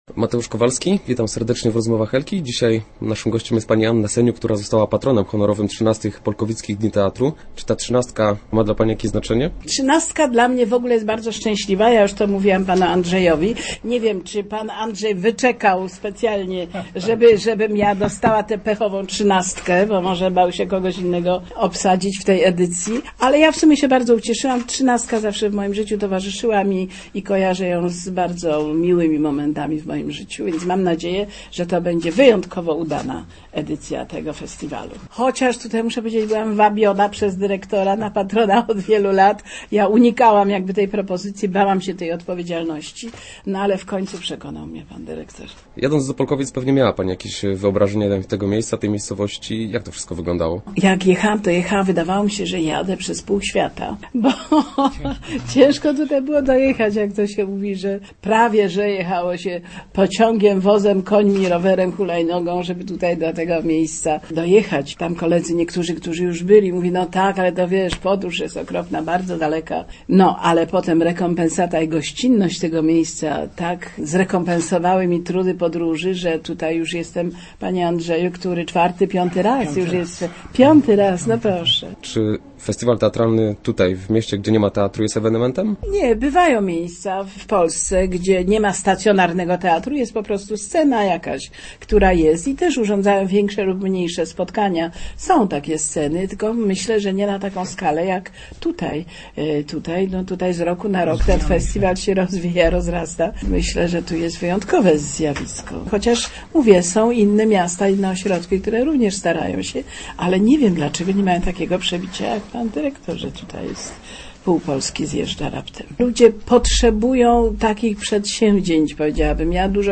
Start arrow Rozmowy Elki arrow Seniuk: Długo walczyłam z Madzią Karwowską